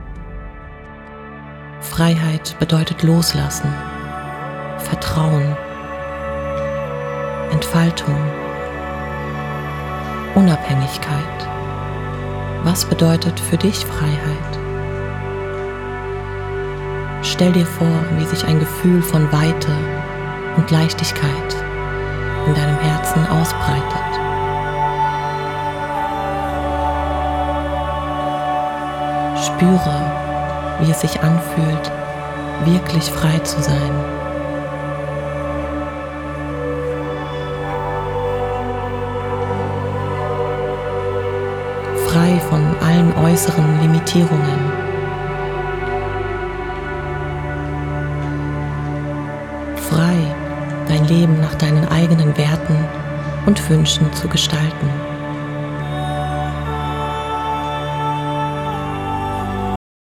Frequenz: 639 Hz – Löst Blockaden und fördert das Gefühl von Fülle und Freiheit.
8D-Musik: Unterstützt die Entspannung und das Aktivieren positiver Überzeugungen.